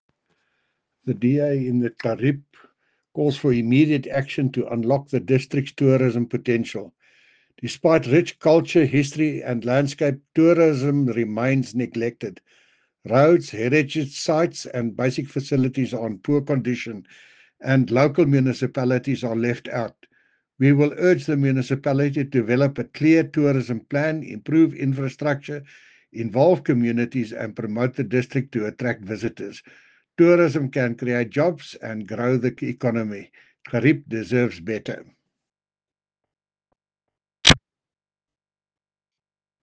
Afrikaans soundbites by Cllr Jacques van Rensburg and Sesotho soundbite by Jafta Mokoena MPL.